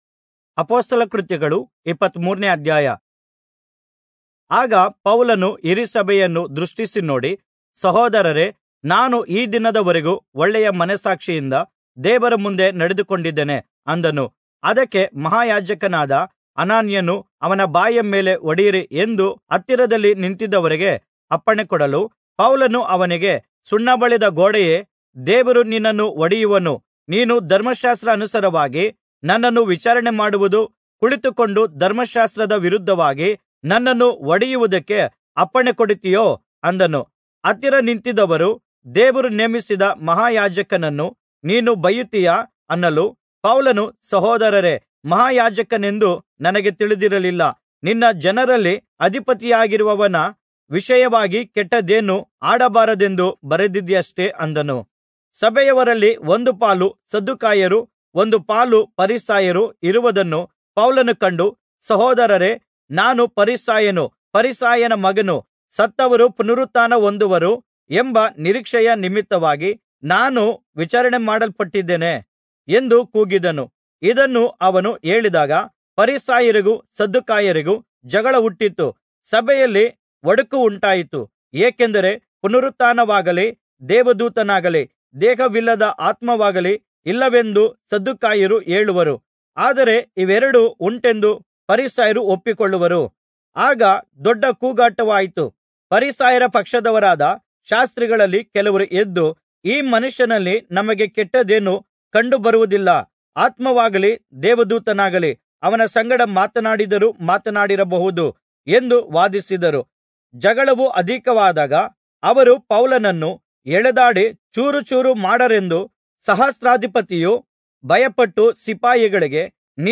Kannada Audio Bible - Acts 5 in Irvkn bible version